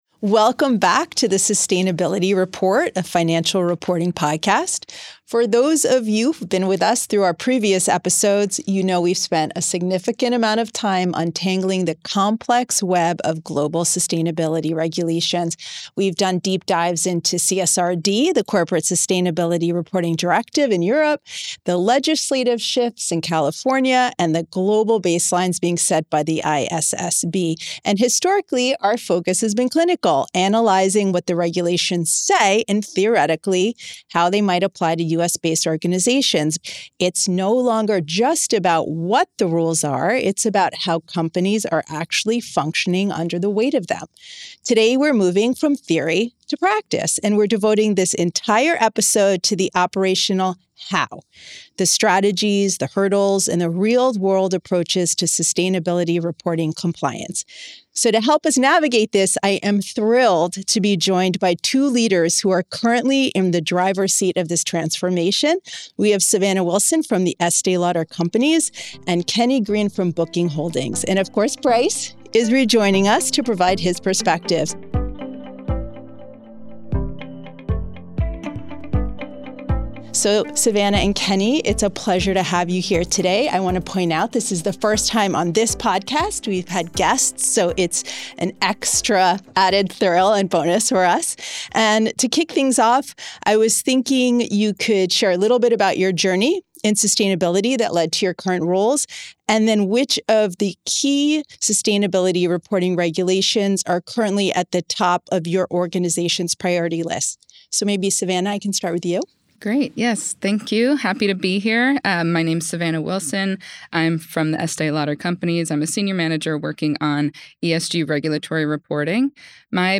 If you’re trying to untangle evolving global requirements or looking for practical ways to move your organization from simply complying with to meaningfully operationalizing sustainability reporting, this conversation offers clear perspective — and a path forward.